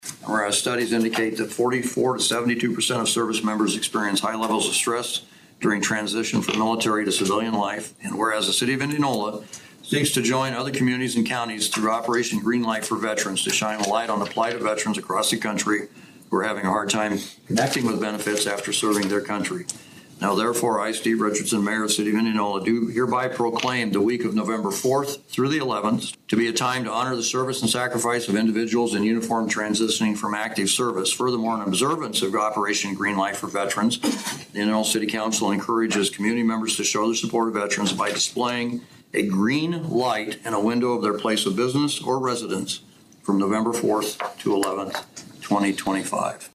Indianola Mayor Steve Richardson read a proclamation in support of Operation Green Light for Veterans at the most recent Indianola City Council meeting. Operation Green Light for Veterans is a nationwide initiative to show solidarity with America’s Veterans, and through November 11th Indianola and area residents are encouraged to use green lights to recognize the sacrifice and strength of our veterans as they transition from military to civilian life.